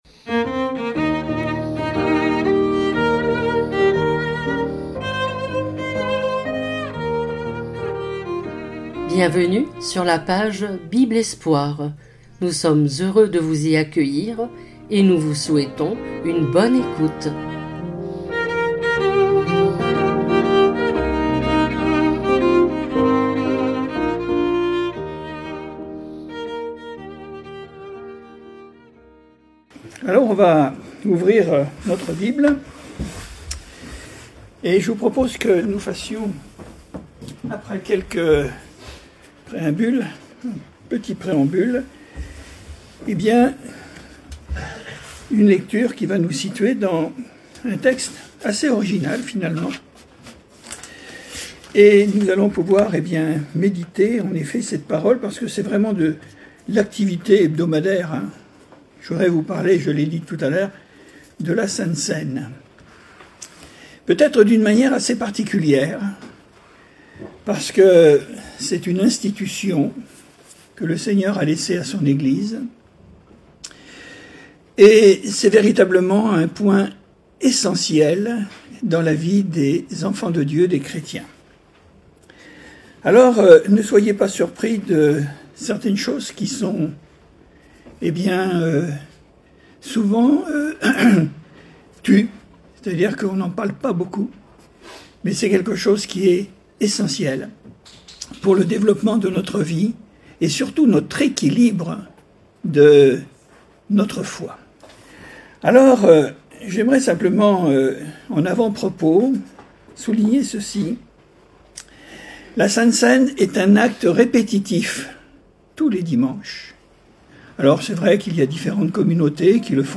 Voici un exposé moral et spirituel de l’humanité sa mentalité, son état d’esprit et ses addictions qui combattent la foi du Chrétien véritable.